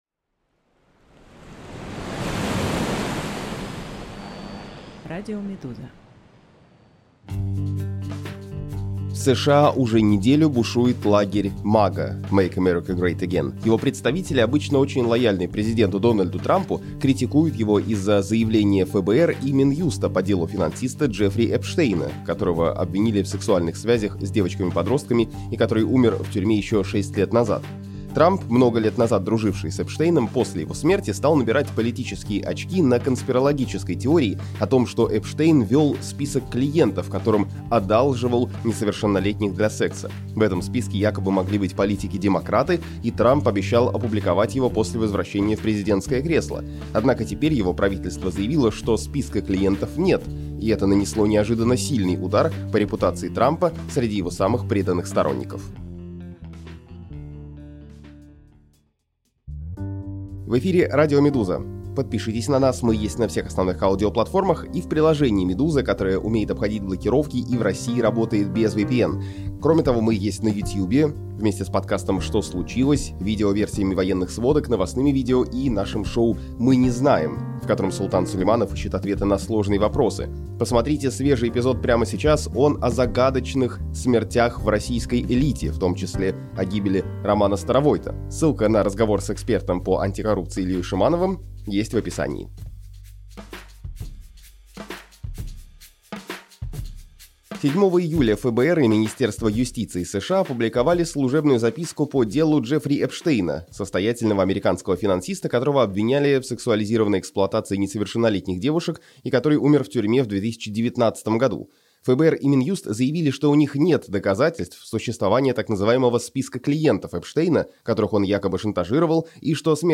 Аудиоверсии главных текстов «Медузы». Расследования, репортажи, разборы и другие материалы — теперь и в звуке.